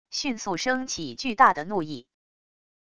迅速生起巨大的怒意wav音频生成系统WAV Audio Player